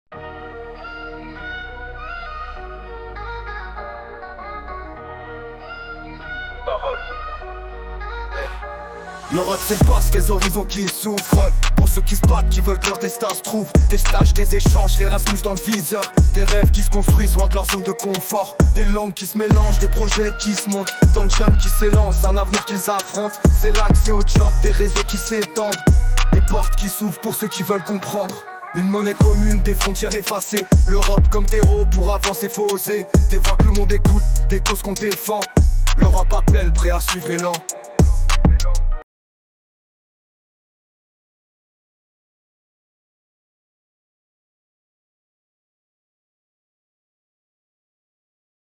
Passeport pour l’avenir is a powerful rap track recorded for the Association Community’s Words of Europe residency, capturing the hopes, dreams, and determination of young Europeans. Through verses that celebrate open horizons, cross-cultural exchanges, and the courage to venture beyond comfort zones, this piece reflects a collective vision of Europe as a space of opportunity.